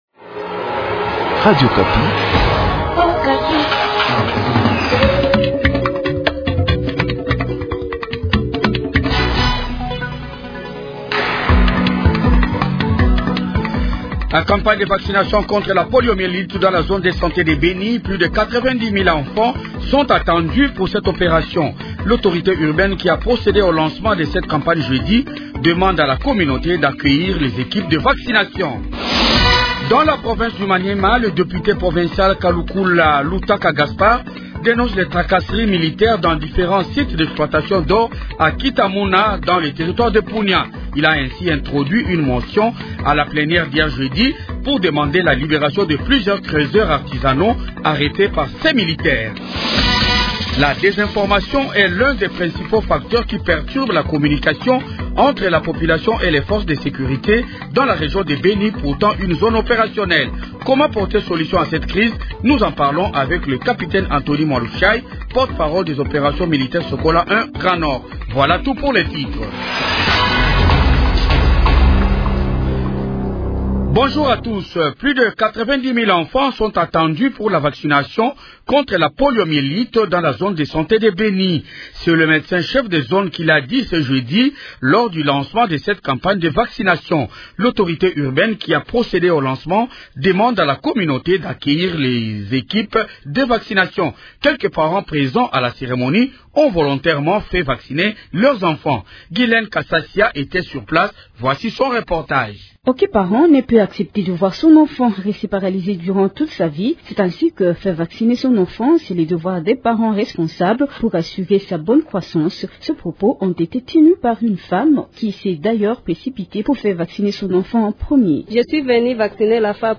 Journal Français Matin 8 heures